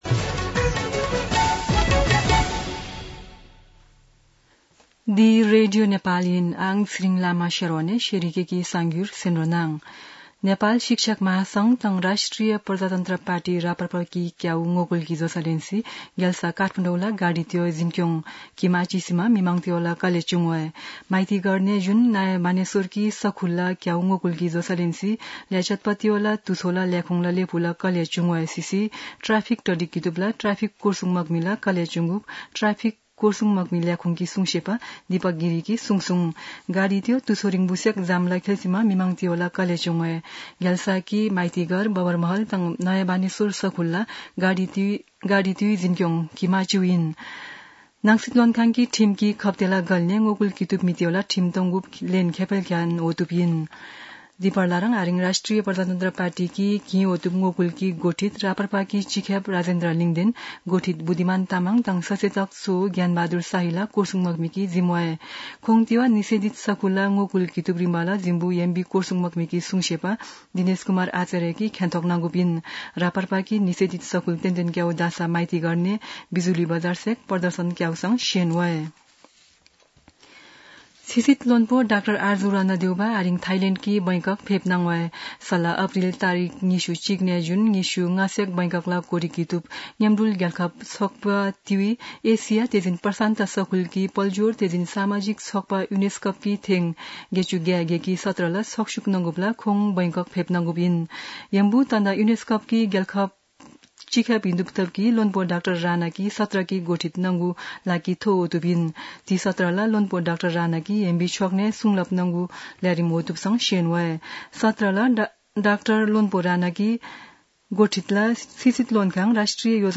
शेर्पा भाषाको समाचार : ७ वैशाख , २०८२